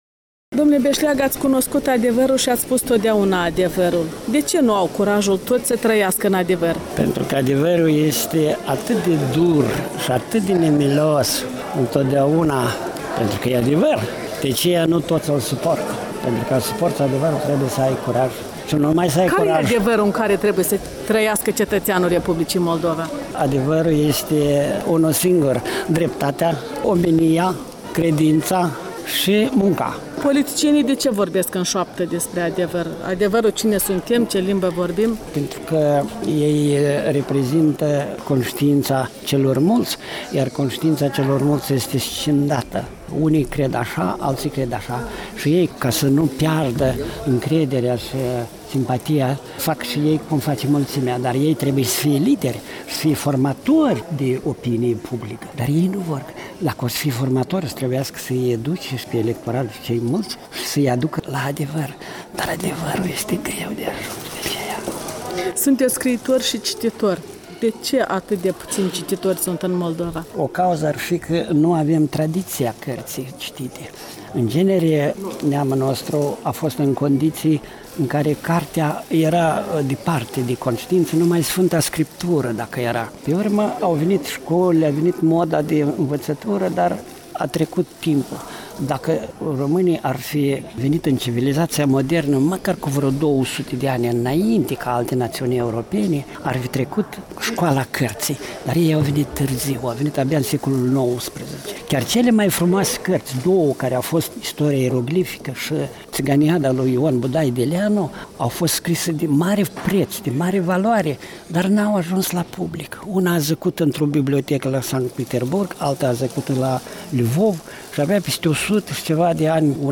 Un interviu cu scriitorul Vladimir Beşleagă